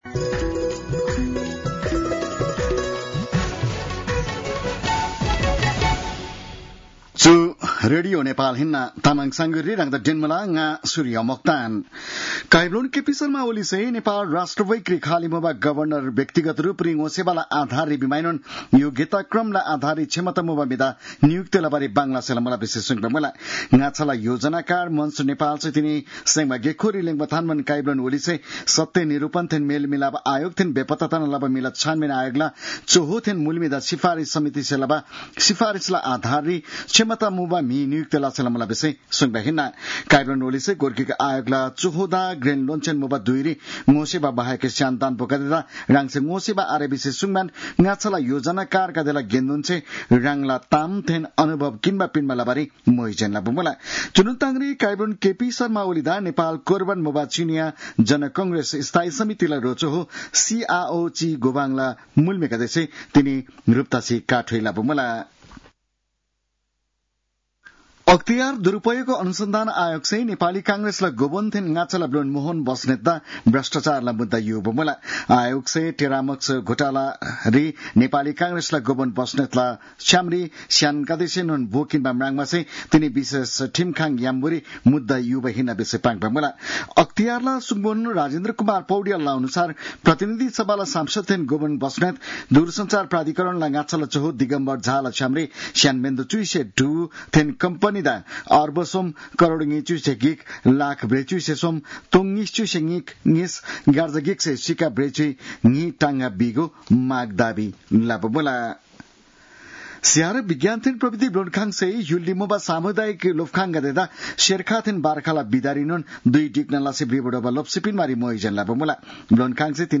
तामाङ भाषाको समाचार : १ जेठ , २०८२